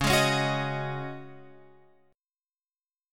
C# Suspended 2nd Suspended 4th